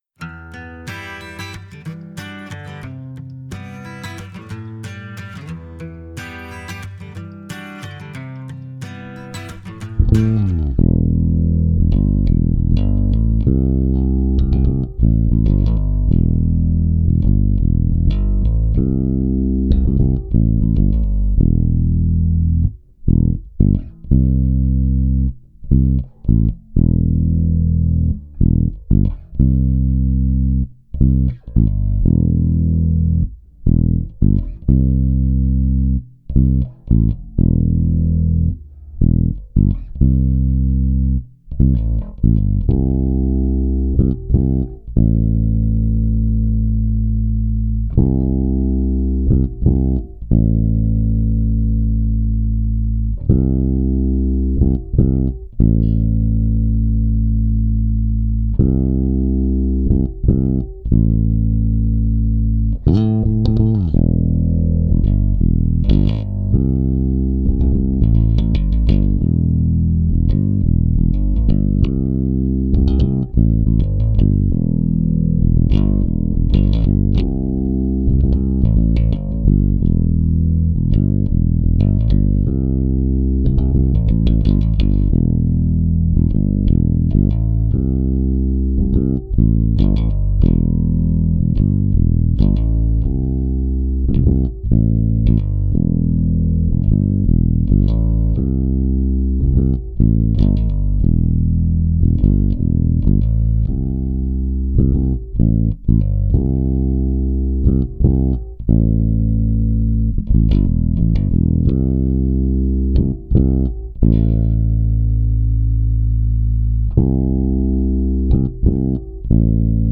basse uniquement